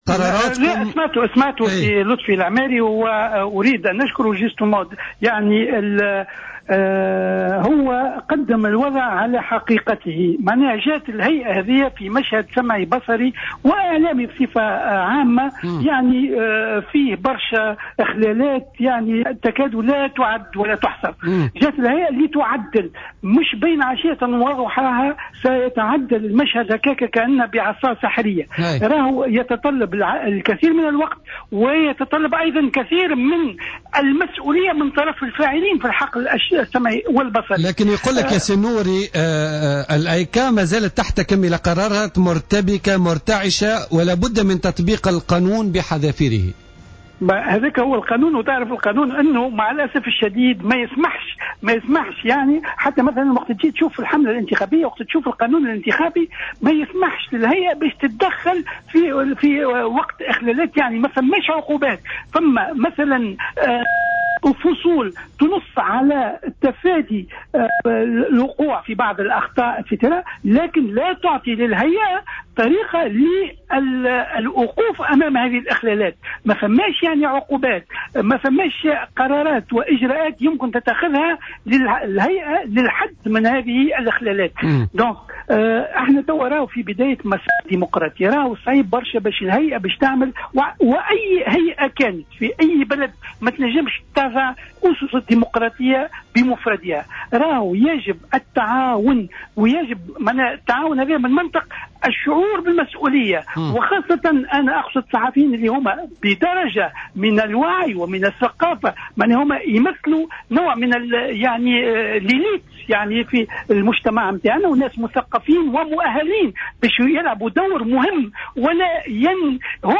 قال رئيس الهيئة العليا المستقلة للاتصال السمعي البصري، النوري اللجمي في مداخلة له خلال حصة اليوم الخميس من برنامج بوليتيكا على جوهرة أف أم إن الهايكا جاءت وسط مشهد إعلامي تشوبه العديد من الاخلالات ولن تقدر على تعديل القطاع بين عشية وضحاها وهو ما يتطلب وقتا، مشددا على الدور التعديلي للهيئة التي تترك فرضية فرض العقوبات على المخالفين كخيار يأتي في المرتبة الأخيرة.